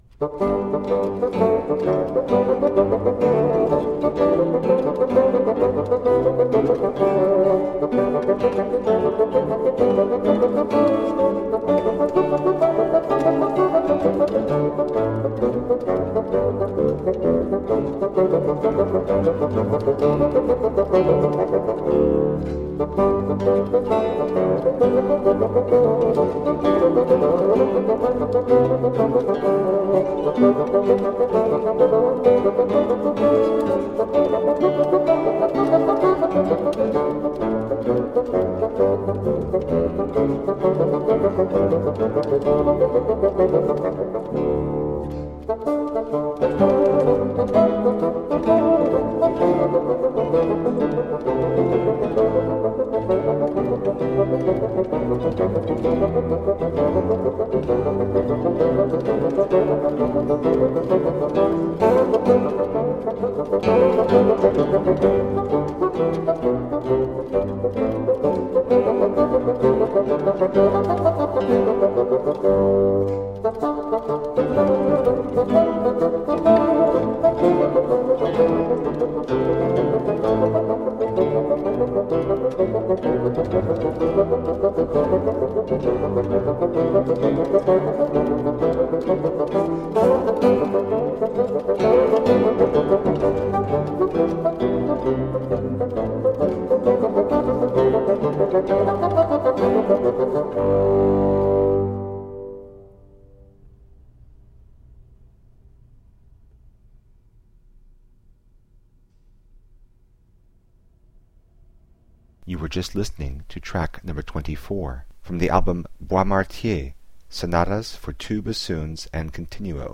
Classical, Chamber Music, Baroque, Instrumental, Bassoon
Harpsichord, Organ